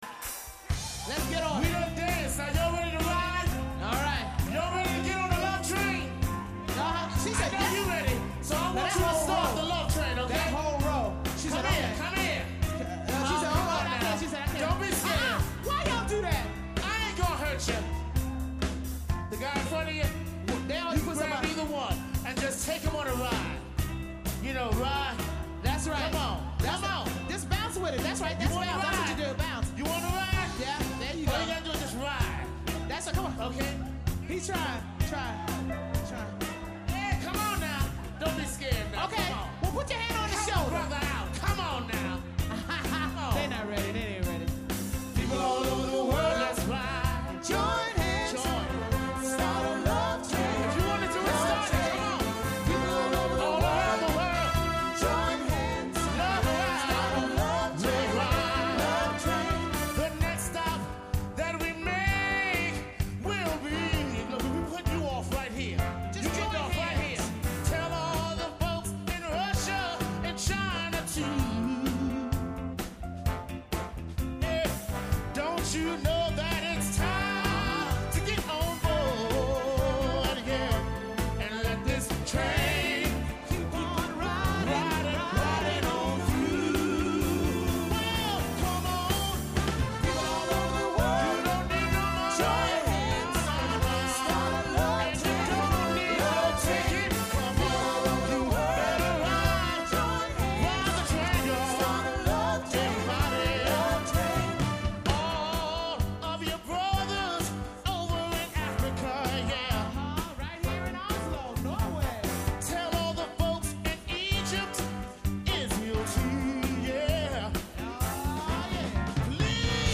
Commercial arrangements for Symphony Orchestra